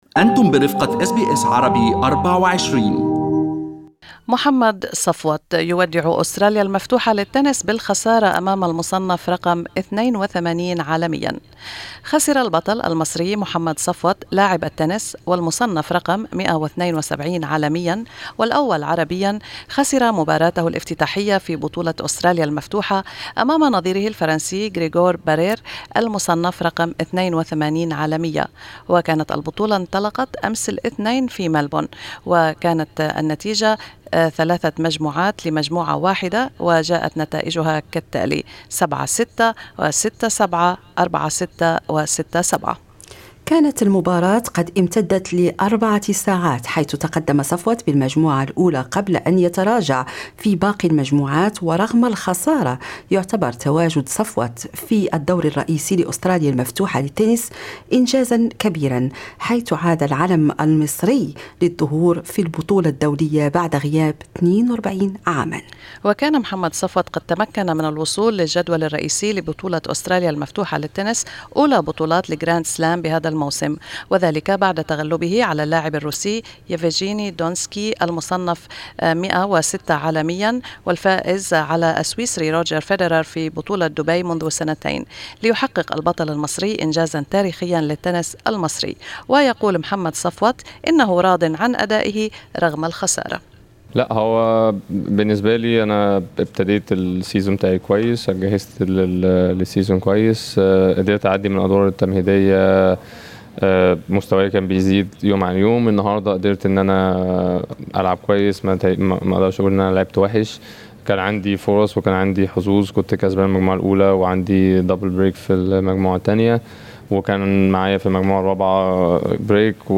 وقال محمد صفوت في حديث خاص مع شبكة SBS إنه راض عن أدائه رغم الخسارة، وإنه يتطلع الآن إلى تمثيل مصر في دورة الألعاب الأولمبية التي ستقام في اليابان هذا العام.